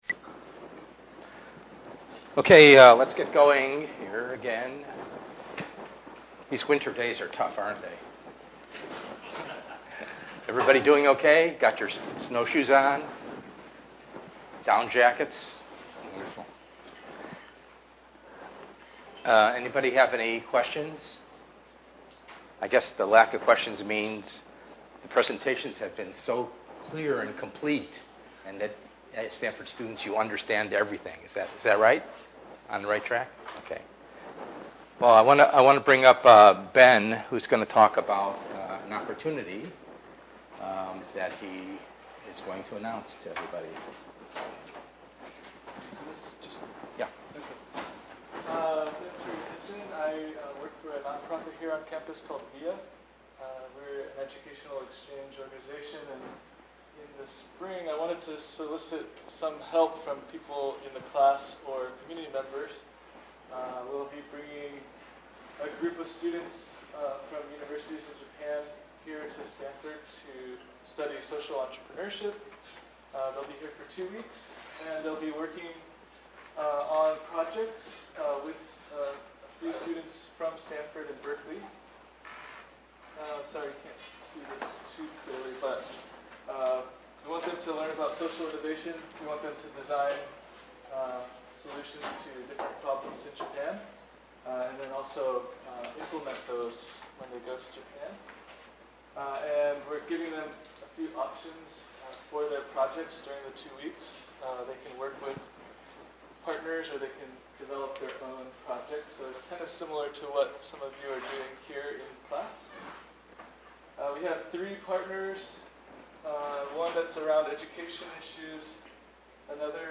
ENGR110/210: Perspectives in Assistive Technology - Lecture 05b